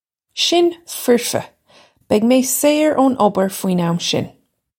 Pronunciation for how to say
Shin firfa. Beg may sayr oh-n ubber fween am shin.
This is an approximate phonetic pronunciation of the phrase.